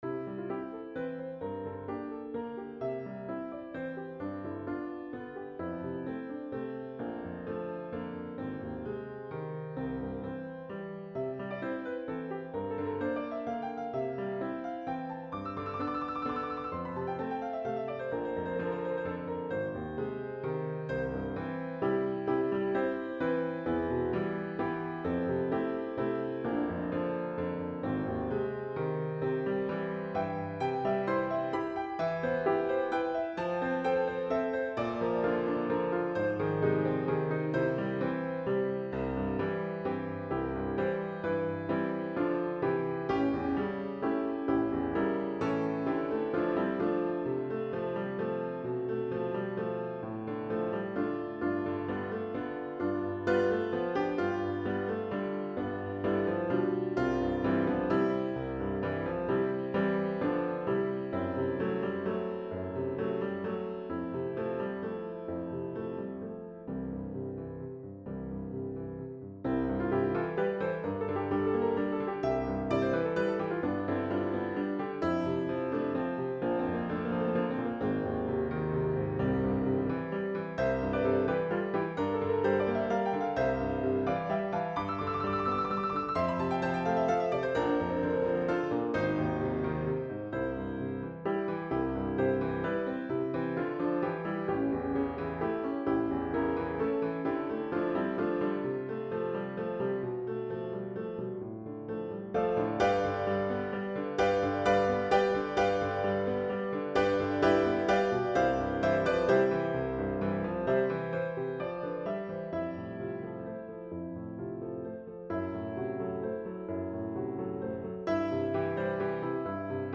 This song is a vibrant waltz, composed in the key of C major. It is essentially in two part, AB form.